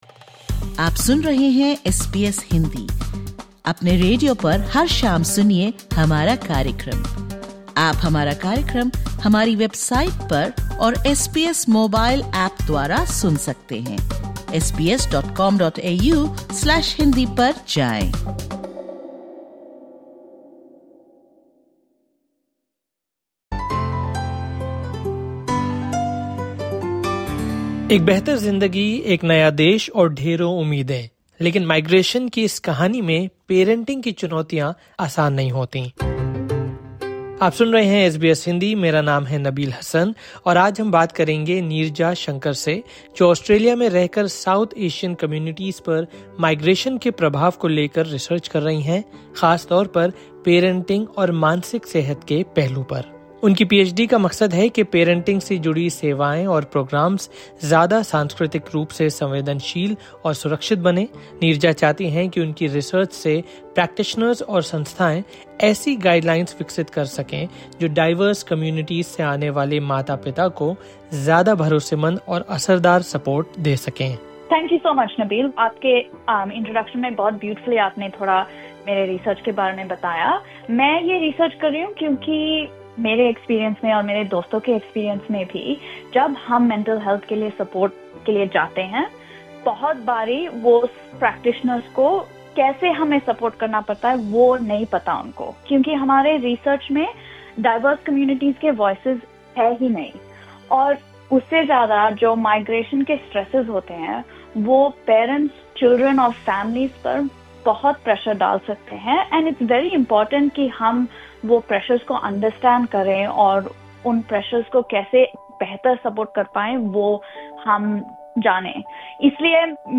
(Disclaimer: The information given in this interview is of general nature.